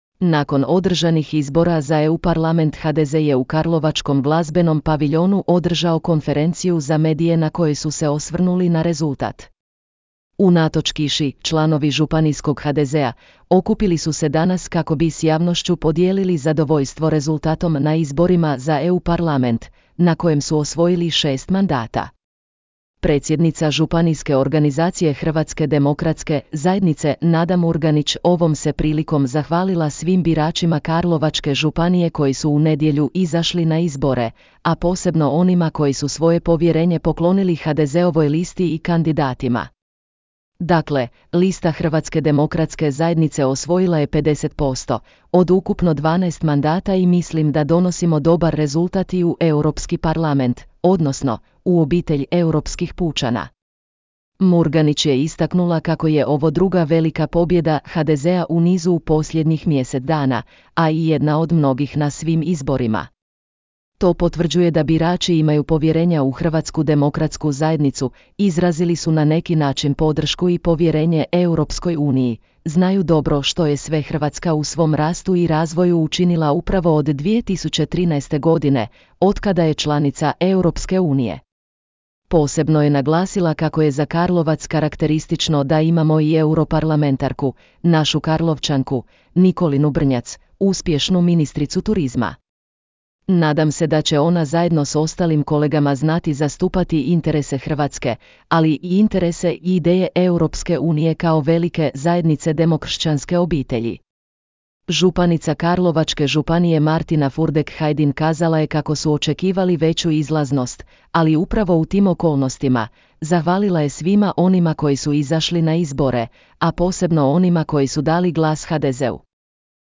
Nakon održanih izbora za EU parlament HDZ je u karlovačkom Glazbenom paviljonu održao konferenciju za medije na kojoj su se osvrnuli na rezultat.
Unatoč kiši, članovi županijskog HDZ-a, okupili su se danas kako bi s javnošću podijelili zadovoljstvo rezultatom na izborima za EU parlament, na kojem su osvojili šest mandata.